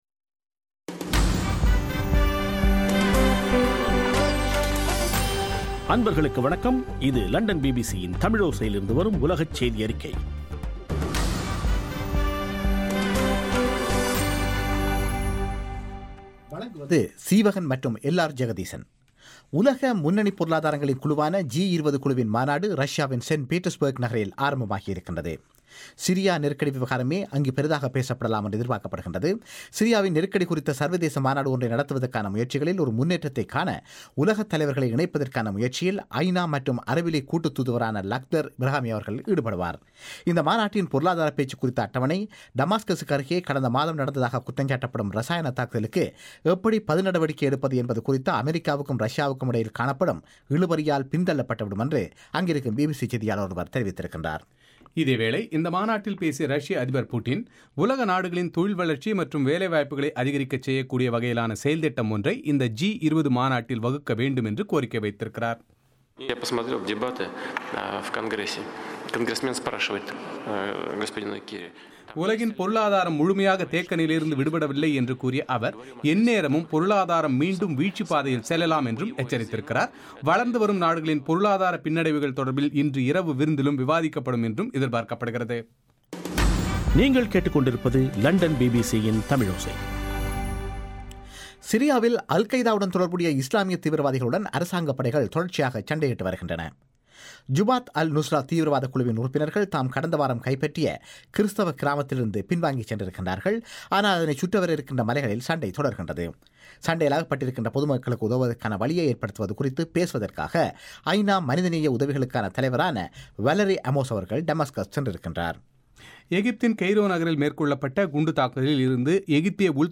செப்டம்பர் 5 பிபிசியின் உலகச் செய்திகள்